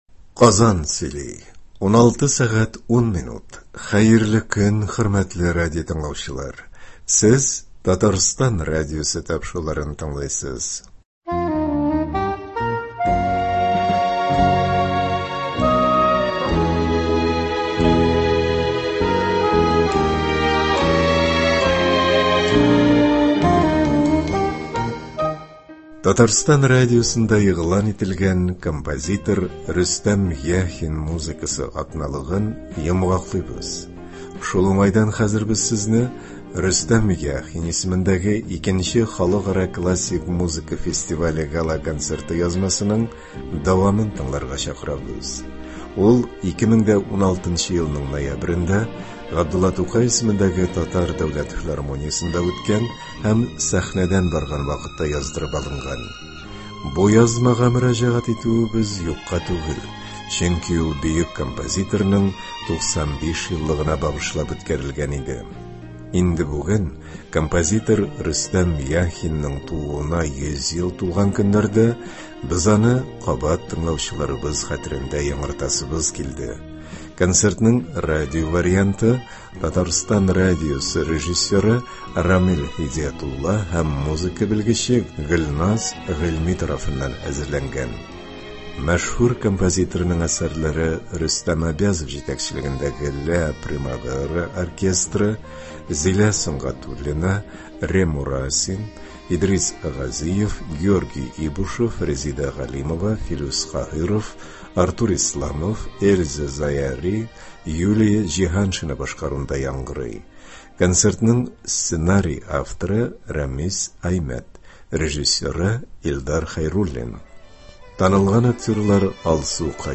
классик музыка фестивале гала-концерты
Ул 2016 елның ноябрендә Г.Тукай исемендәге Татар Дәүләт филармониясендә үткән һәм сәхнәдән барган вакытта яздырып алынган иде.